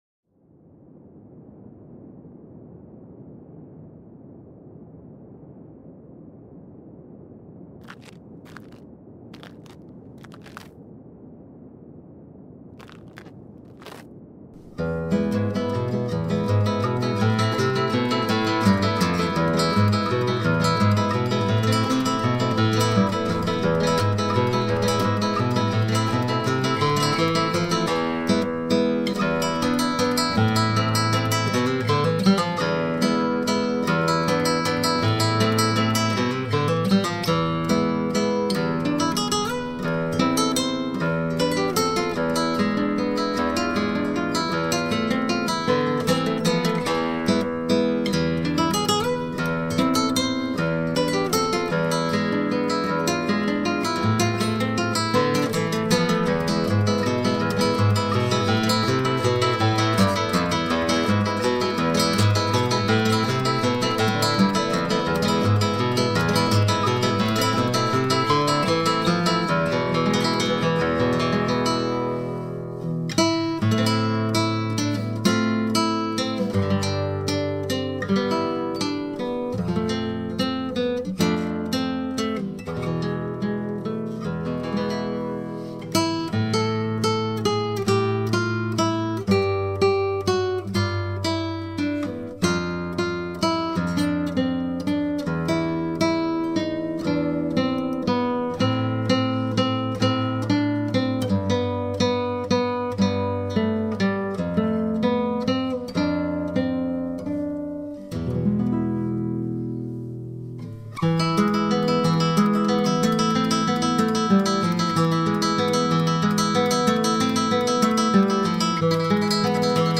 classical guitar